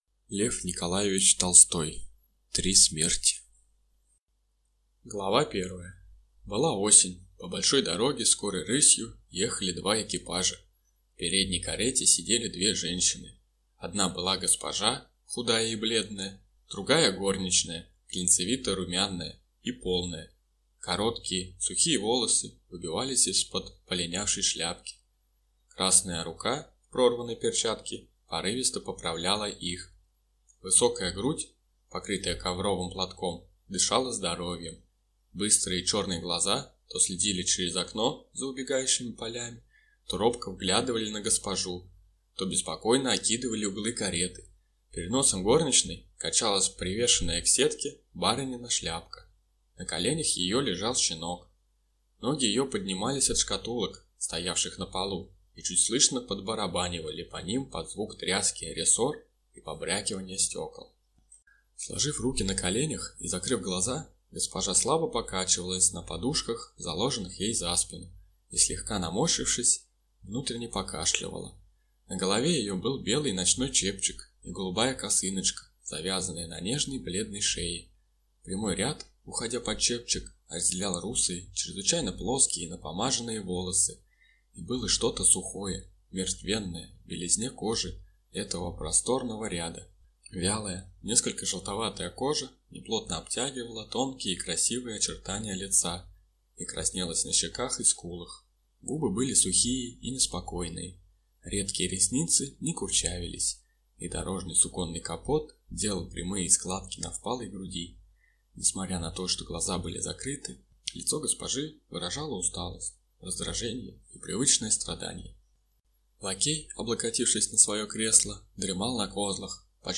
Аудиокнига Три смерти | Библиотека аудиокниг